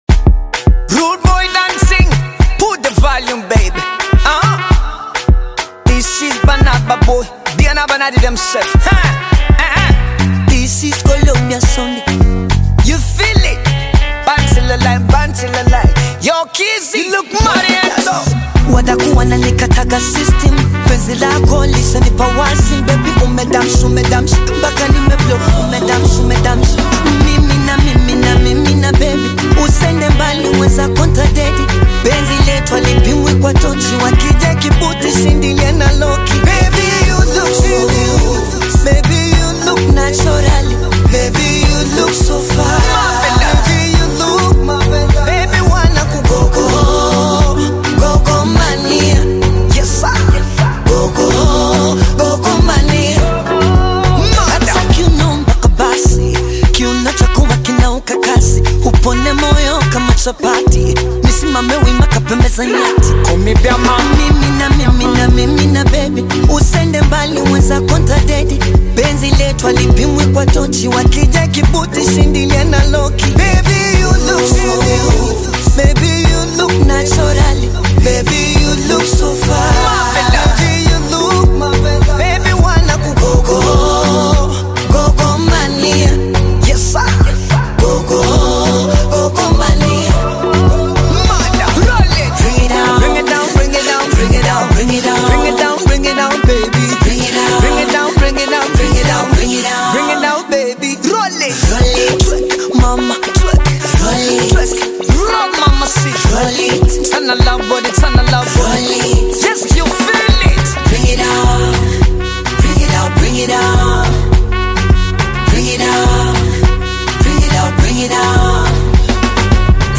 vibrant Bongo Flava single
smooth Tanzanian vocals with Afrobeat rhythms
Genre: Bongo Flava